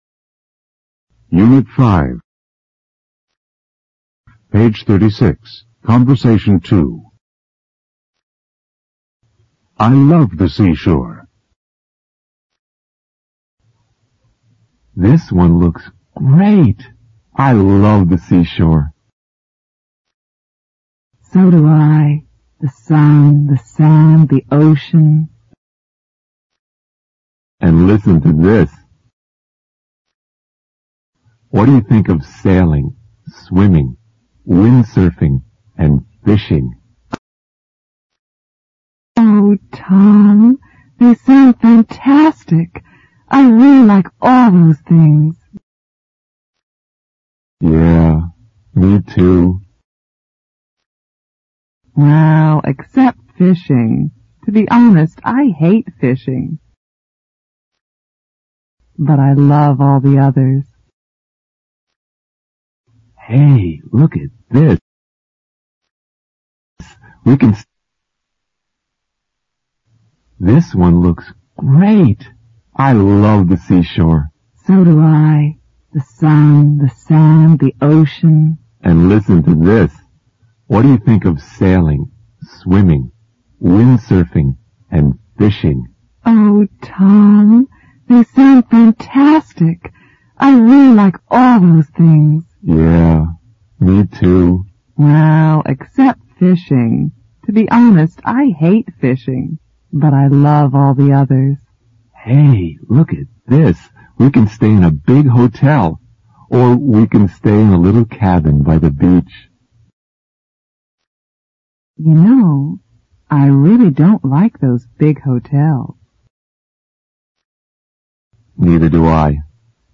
简单英语口语对话 unit5_conbersation2_new(mp3+lrc字幕)